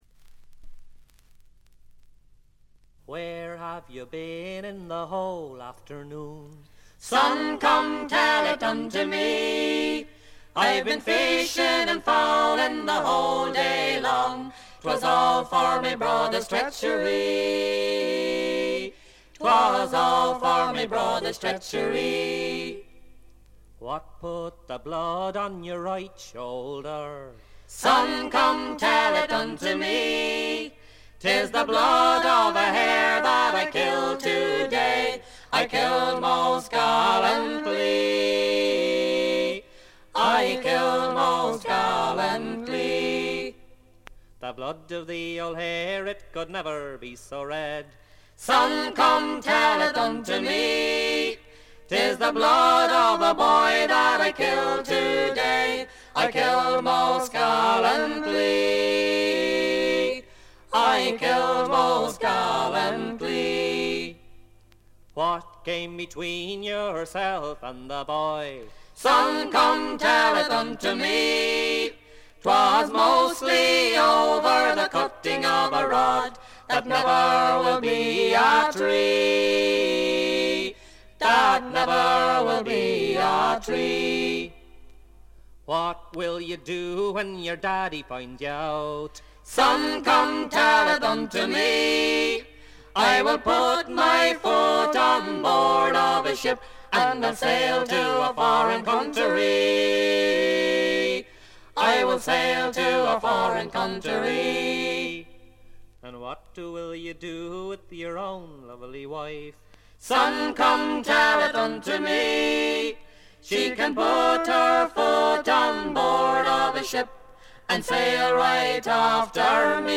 軽微なチリプチ程度。
モダン・フォークの香りただよう美しいコーラスワークが胸を打ちます。
アイリッシュトラッドの基本盤。
試聴曲は現品からの取り込み音源です。